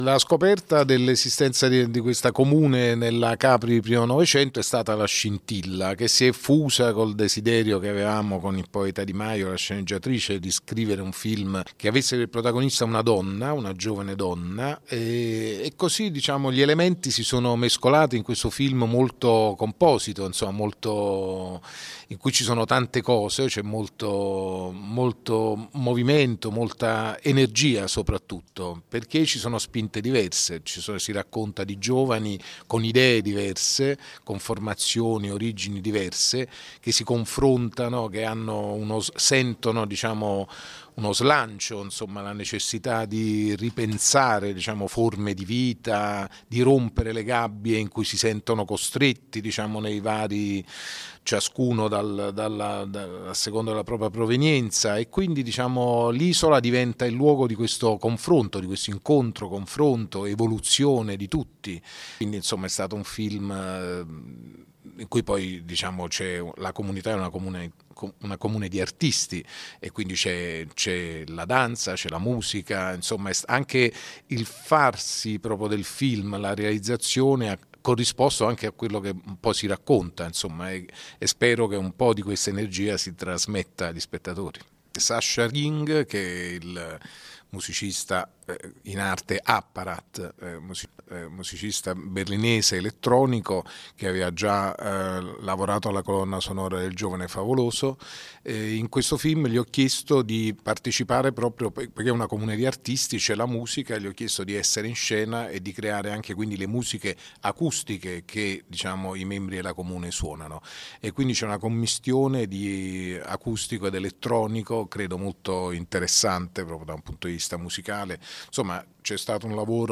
capri-revolution-mario-martone-presenta-il-film.mp3